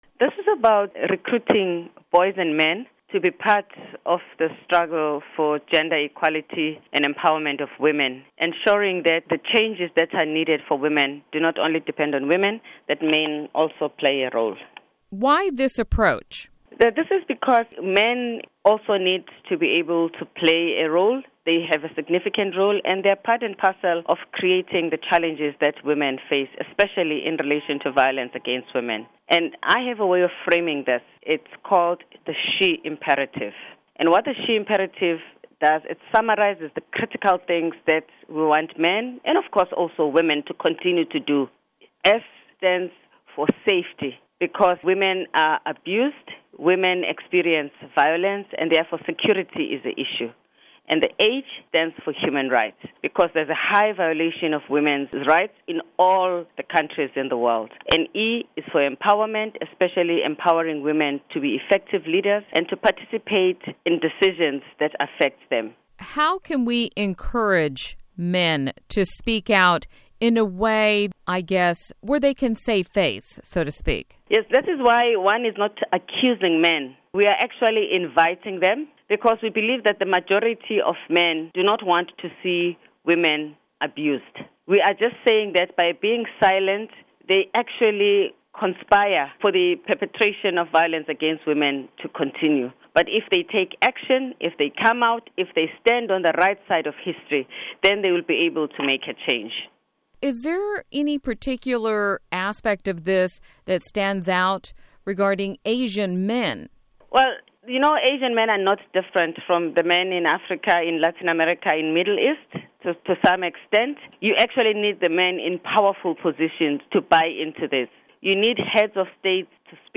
Q&A with Phumzile Mlambo-Ngcuka: 'He for She'